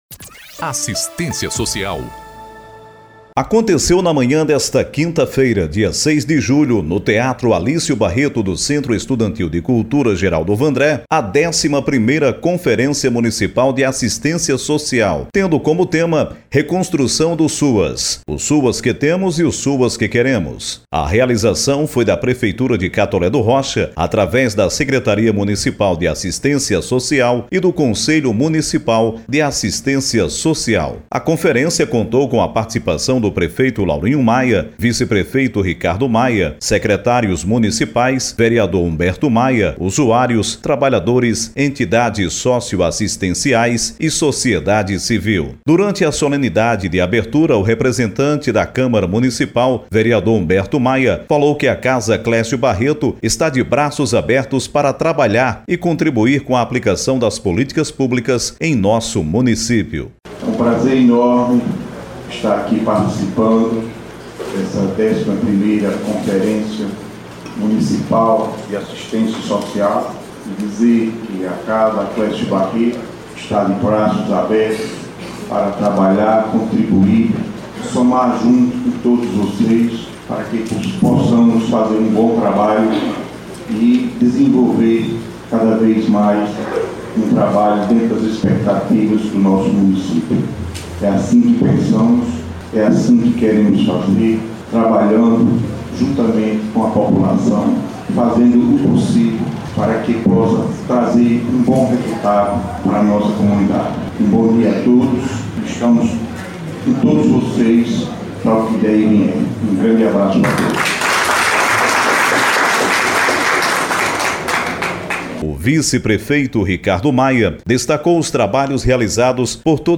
Reportagem-02-11a-Conferencia-Municipal-de-Assistencia-Socia.mp3